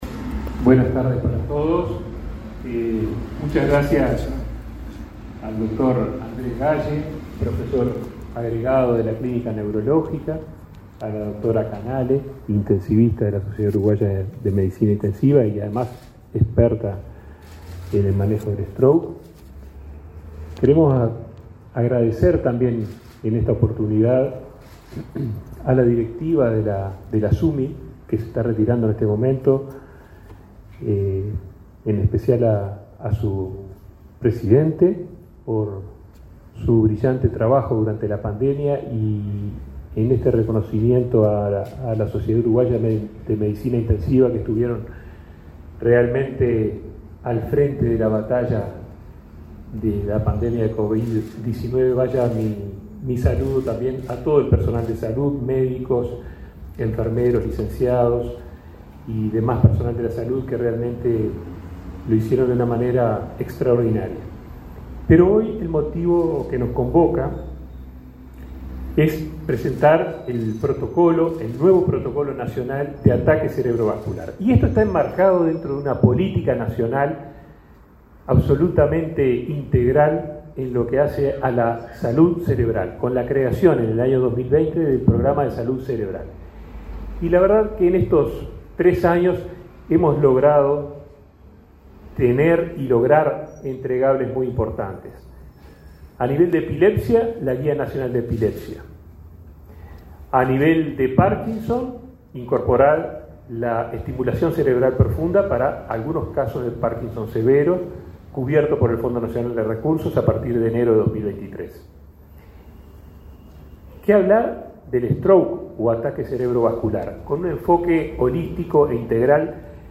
Conferencia de prensa por actualización de protocolos del Plan Nacional de ACV